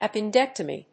音節ap・pen・dec・to・my 発音記号・読み方
/`æpəndéktəmi(米国英語), ˌæpɪˈndektʌmi:(英国英語)/
フリガナアッピンデクタミー